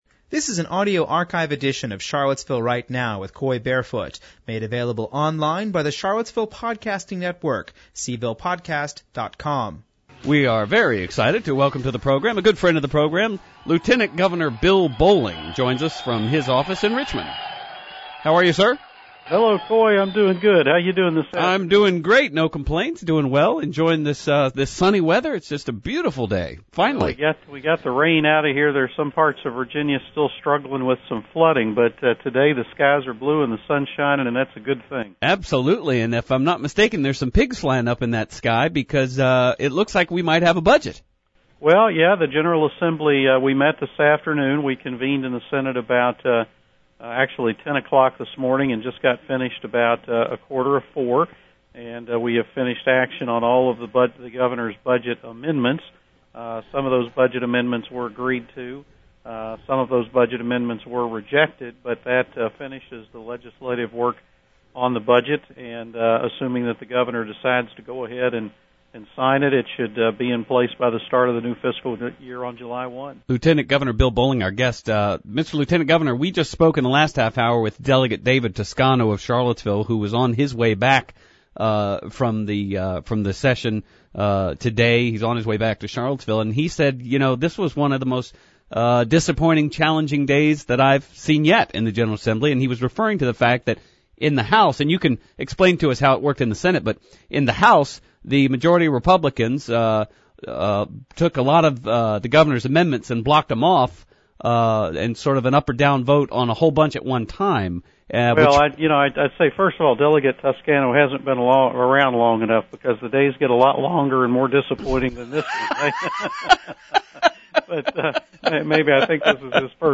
Lt. Governor Bill Bolling phoned in on the June 28 edition of WINA’s Charlottesville–Right Now to talk about Virginia’s new state budget. He also discusses his efforts to shore up Virginia’s laws to prevent against government seizures.